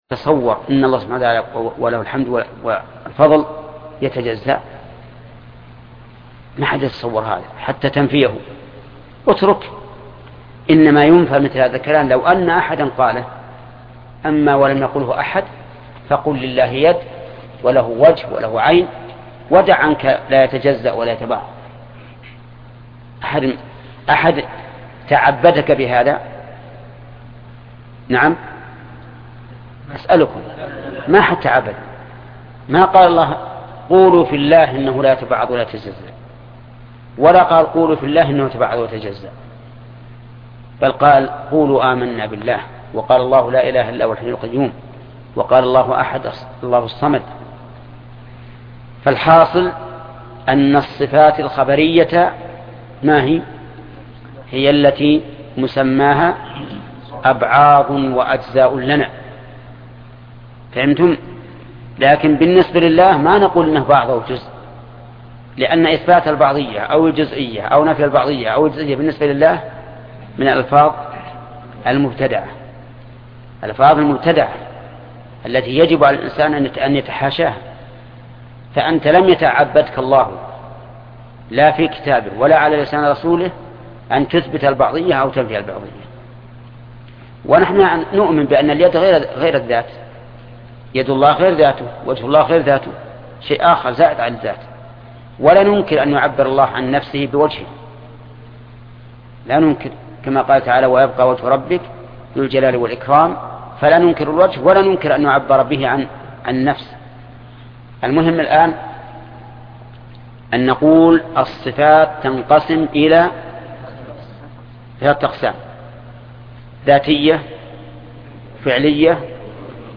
الدرس الثاني عشر
تتمة الكلام على القسم الثالث وهي الصفات الخبرية، ولا نقول فيها هي أجزاء وأبعاض مناقشة الشيخ للطلاب حول أقسام الصفات معنى قوله:( صفاته كذاته قديمة ) وتعريف القديم في اللغة وعند المتكلمين